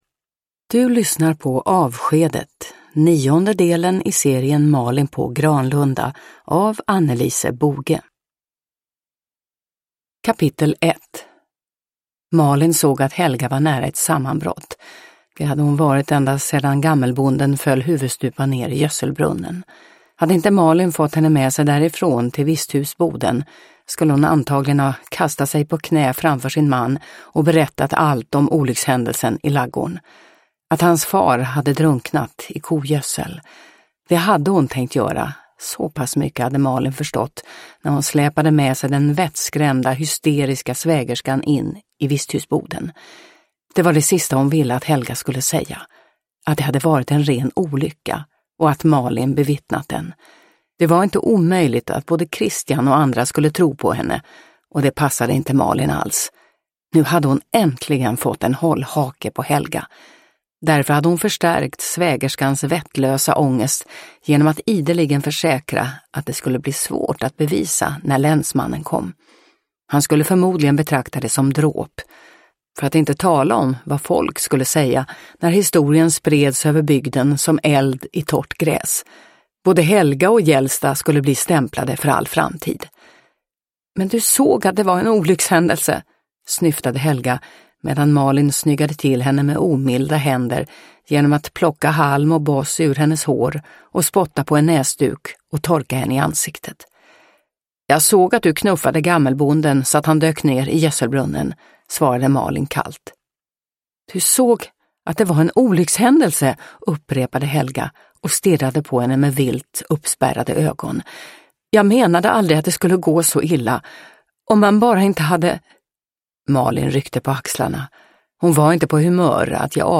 Avskedet – Ljudbok – Laddas ner